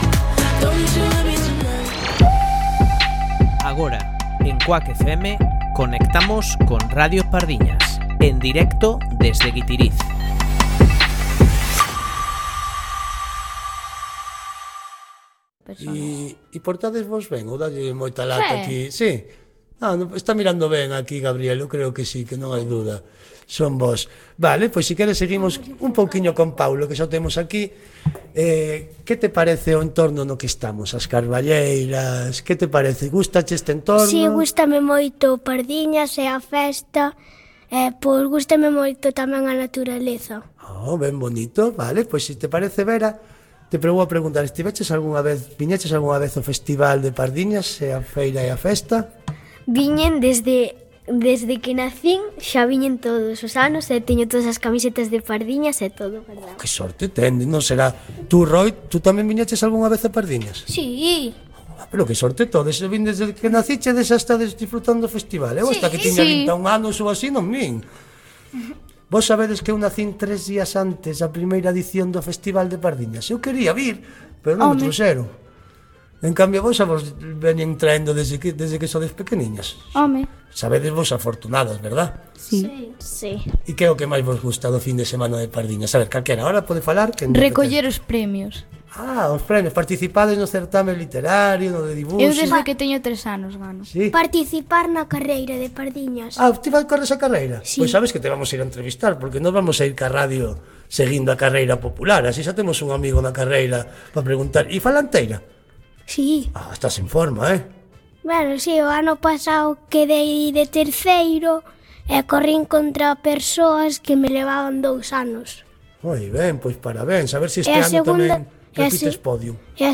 Conexión en directo con Radio Pardiñas, a emisión radiofónica desde o Festival de Pardiñas (Pardiñas, Guitiriz, Lugo).